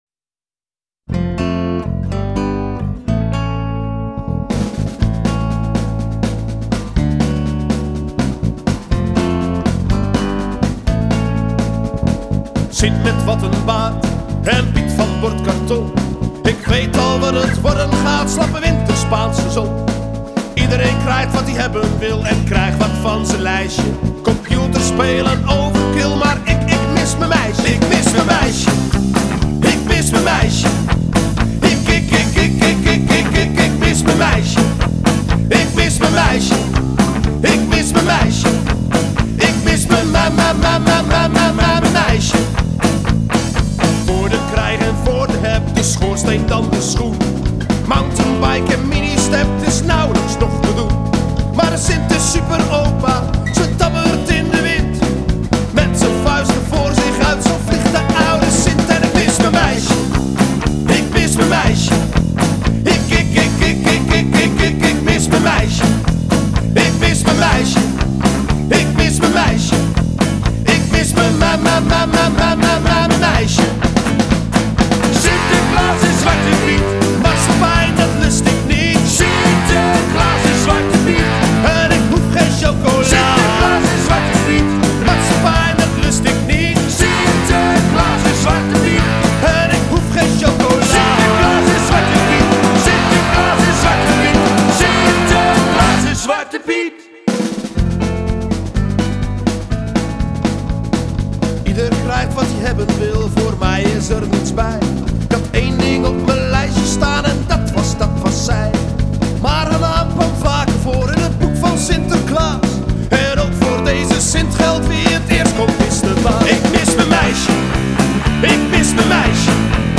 een Nederlandstalige rockband